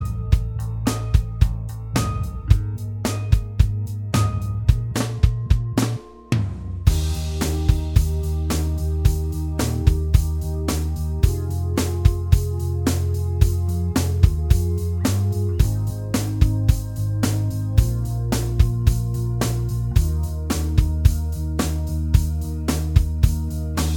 Minus All Guitars Pop (2000s) 3:51 Buy £1.50